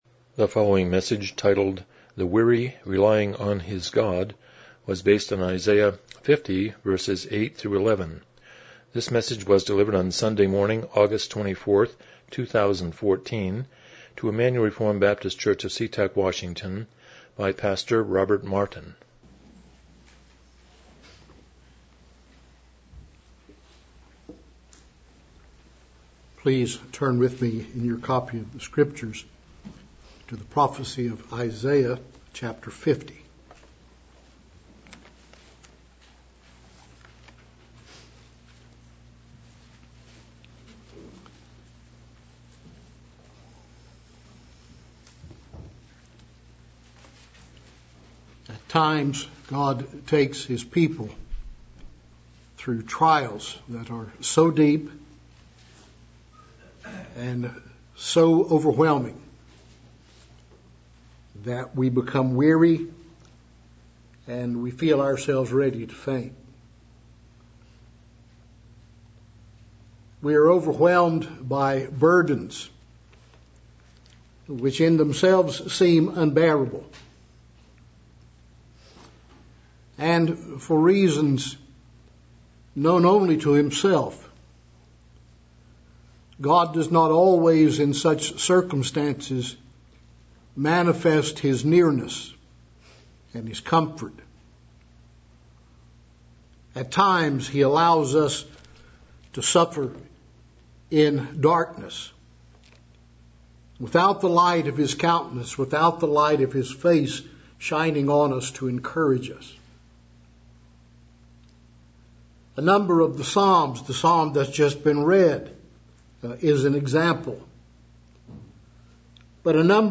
Isaiah 50:8-11 Service Type: Morning Worship « The Parable of the Sower